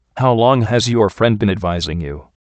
Location: USA